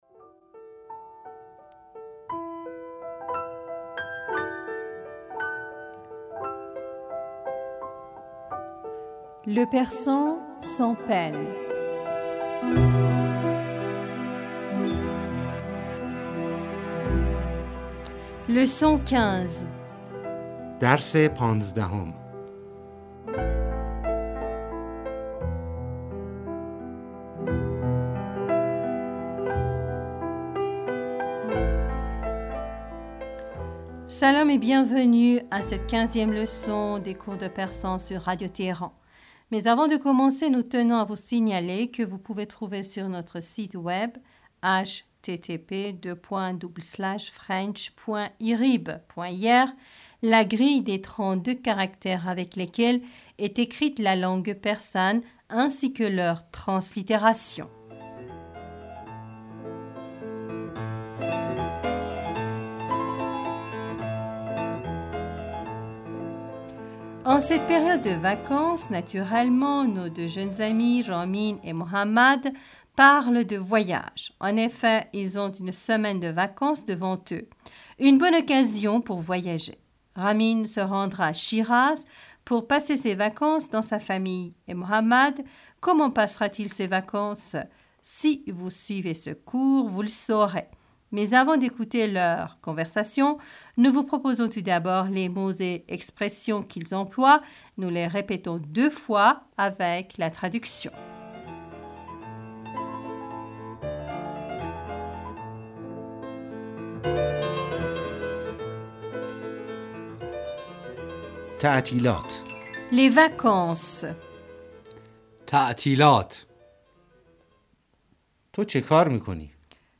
Madame, Monsieur, Salam et bienvenus à nos cours de persan.
Nous les répétons deux fois, avec la traduction.
Nous répétons chaque phrase avec la traduction.